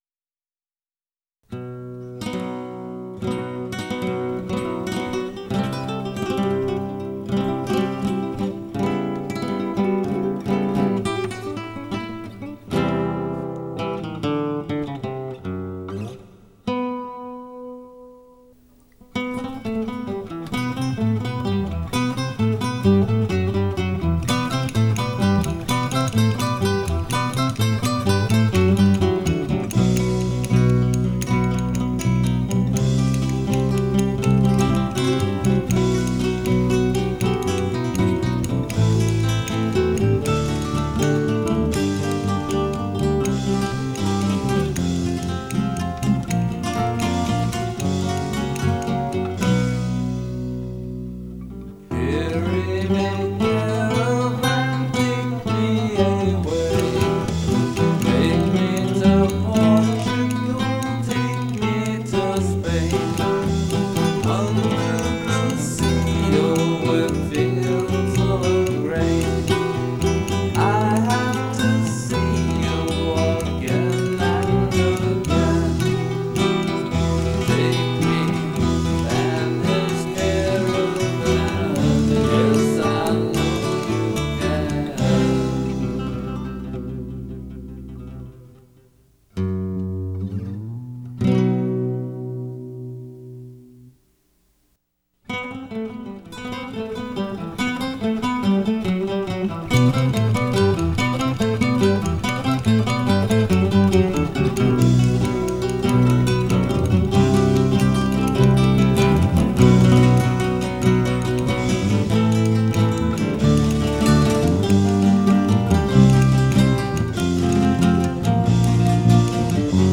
Category Rock/Pop
Studio/Live Studio
guitars, bass, vocals, percussion and sequencing
drums